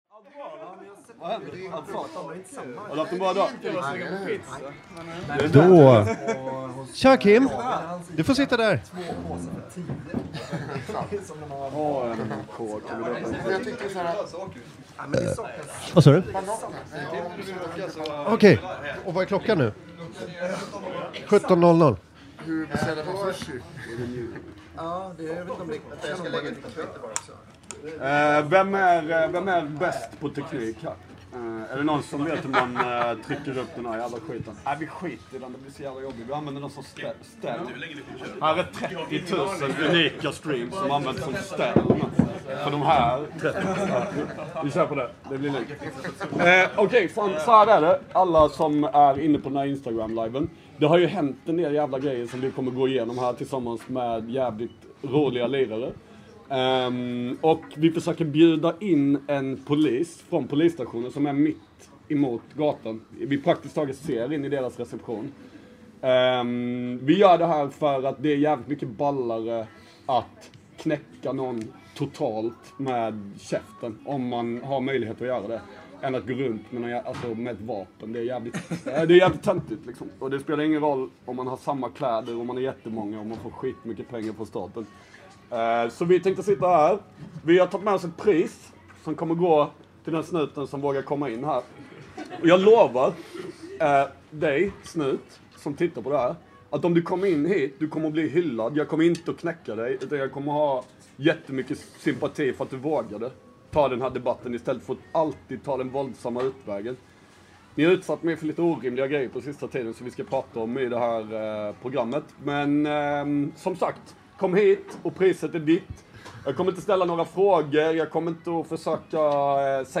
Sushirestaurangen Okonomi bara två dörrar bort från Feca, öppnar sina hjärtan och dörrar för oss och låter oss spela in hos dem.